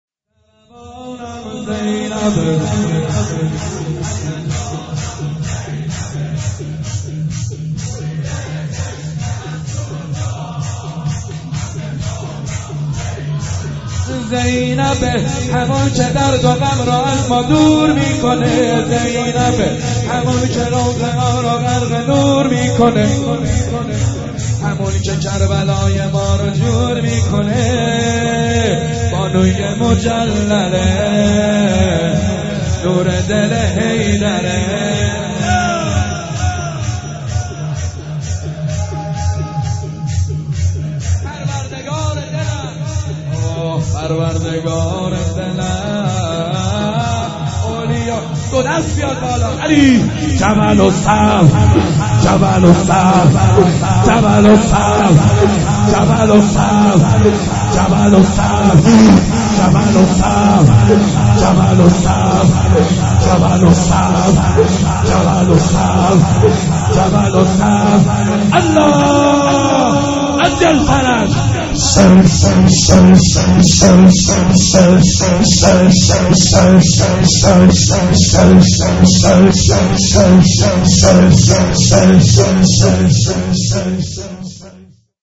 شور - ضربانم زینب هیجانم زینب